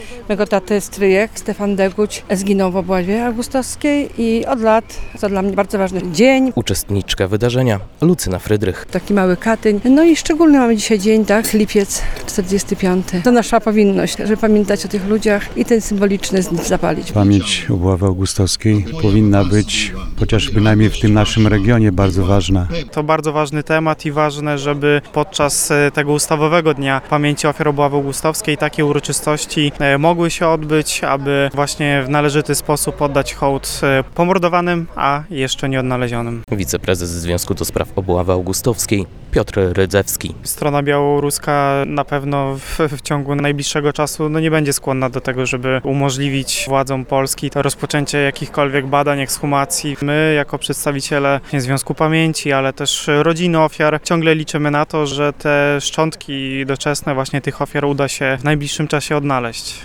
W Dniu Pamięci Ofiar Obławy Augustowskiej w Gibach, rodziny i mieszkańcy regionu upamiętnili tych, którzy oddali swoje życie za wolność ojczyzny.
Tam odbył się apel ofiar obławy, salwa honorowa oraz złożono kwiaty pod pamiątkowym obeliskiem.